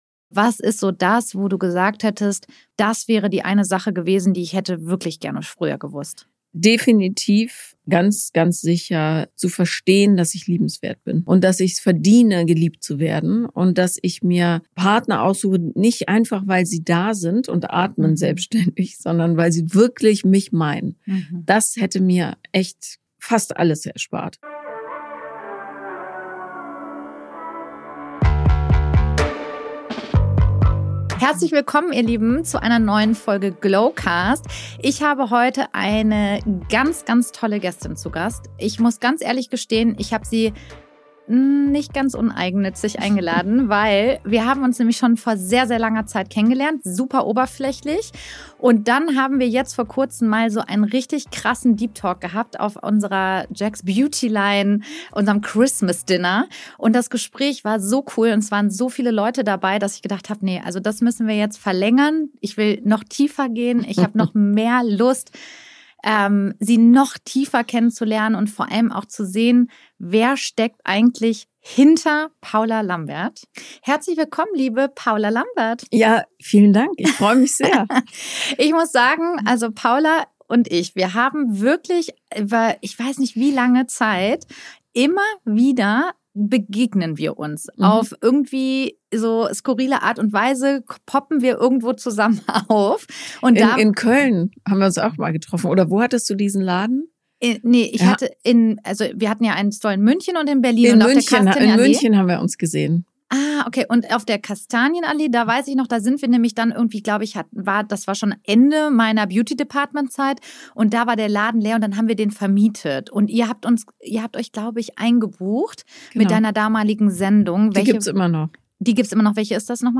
In diesem intensiven Gespräch öffnet sich die bekannte Beziehungsexpertin und Podcasterin wie nie zuvor. Paula spricht über ihre schwierige Kindheit, ihre Ängste und ihren Weg zur Selbstakzeptanz. Sie teilt ihre persönlichen Erfahrungen mit traumatischen Erlebnissen, dem Gefühl der Verlassenheit und wie diese frühen Prägungen ihr Leben bis heute beeinflussen.